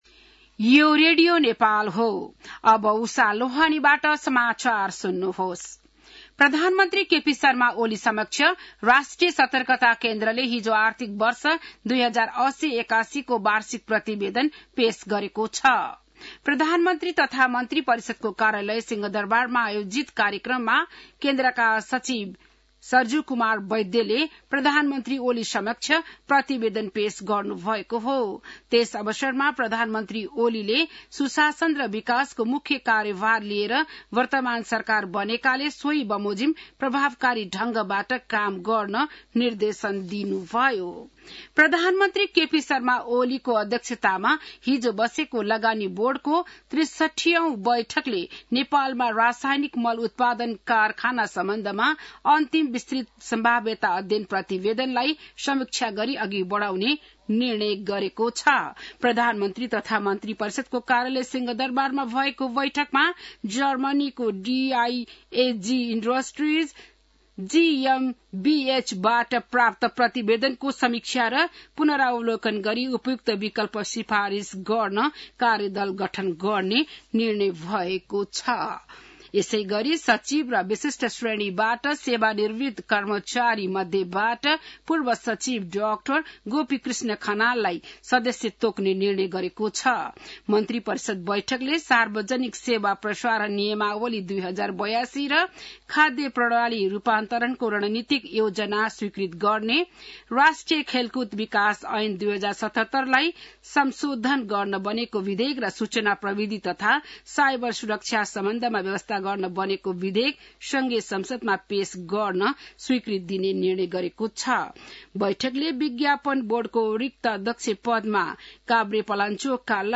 बिहान १० बजेको नेपाली समाचार : १४ जेठ , २०८२